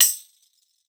Index of /90_sSampleCDs/Best Service ProSamples vol.42 - Session Instruments [AIFF, EXS24, HALion, WAV] 1CD/PS-42 AIFF Session Instruments/Percussion